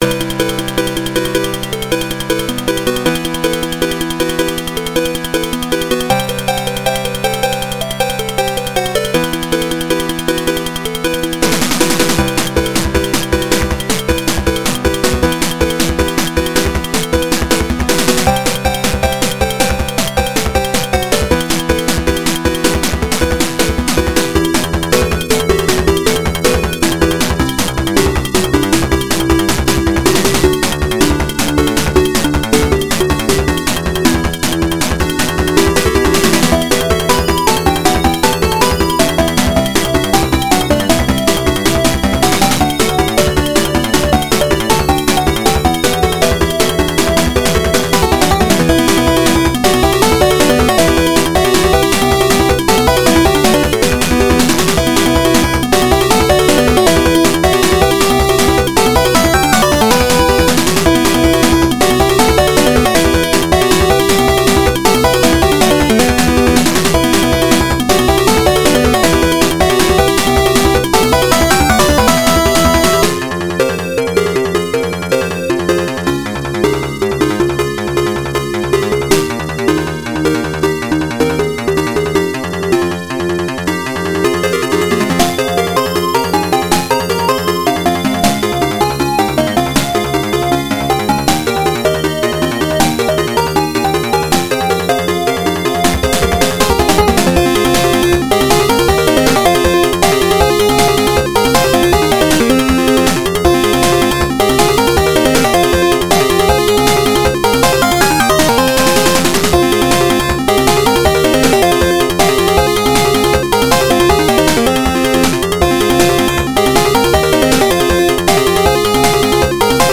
原游戏FM26K版，由PMDPlay导出。